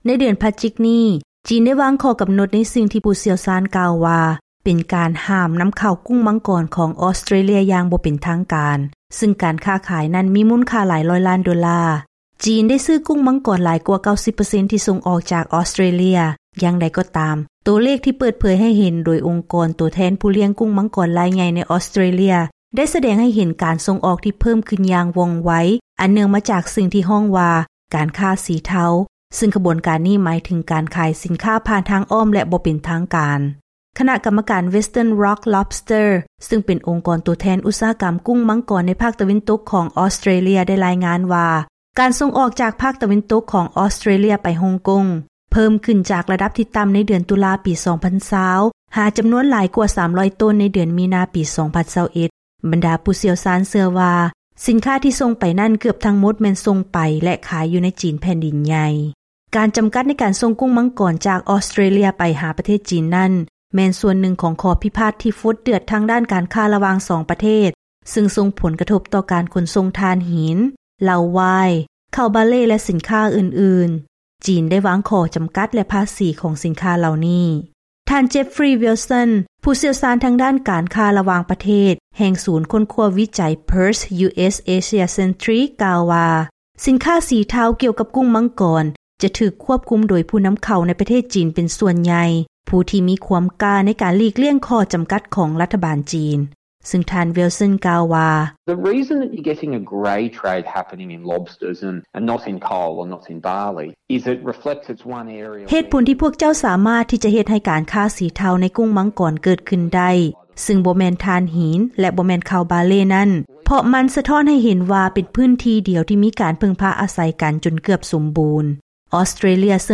ເຊີນຟັງລາຍງານກ່ຽວກັບ ຜູ້ລ້ຽງກຸ້ງມັງກອນຢູ່ໃນ ອອສເຕຣເລຍ ຄົ້ນພົບການເຂົ້າສູ່ຕະຫລາດທີ່ມີຂໍ້ຈໍາກັດຂອງຈີນ